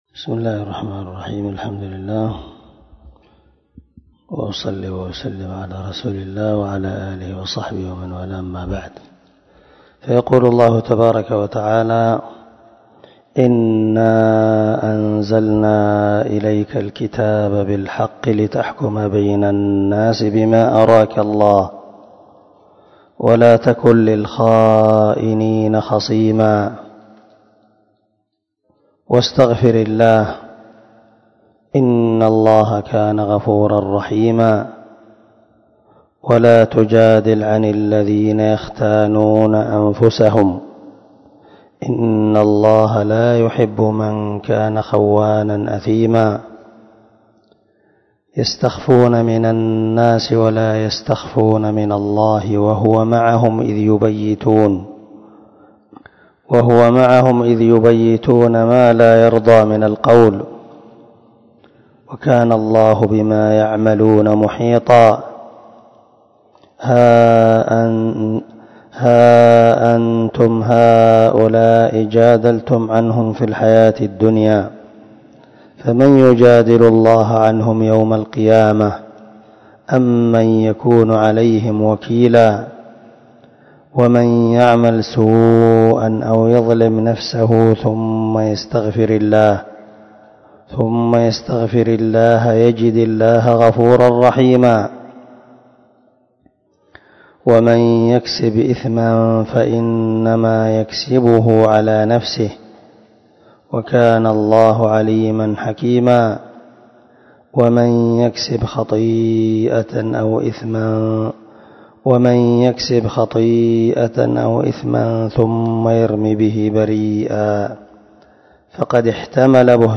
303الدرس 71 تفسير آية ( 105 -113 )من سورة النساء من تفسير القران الكريم مع قراءة لتفسير السعدي
دار الحديث- المَحاوِلة- الصبيحة.